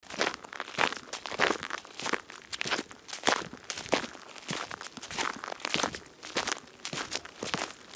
Babushka / audio / sfx / Footsteps / SFX_Footsteps_Gravel_01.wav
SFX_Footsteps_Gravel_01.wav